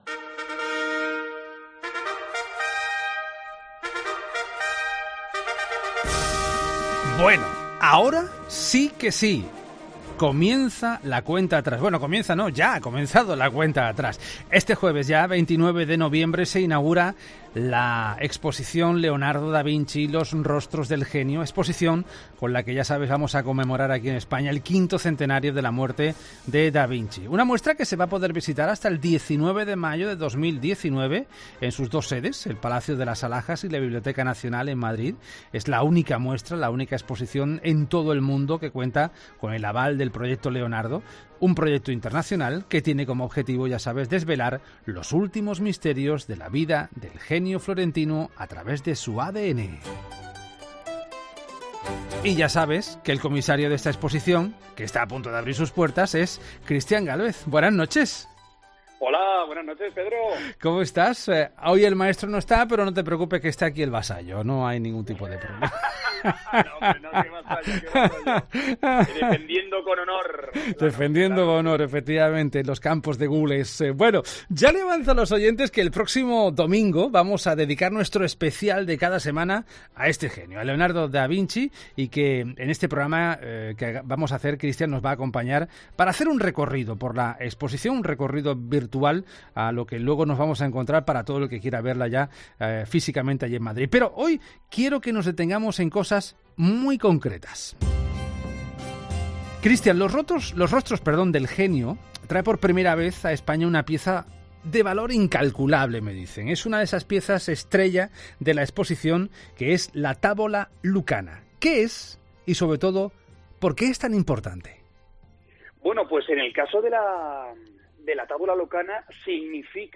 Christian Gálvez repasa un lunes más en La Noche de COPE un acontecimiento relevante en la historia del relevante en la historia del genio Leonardo.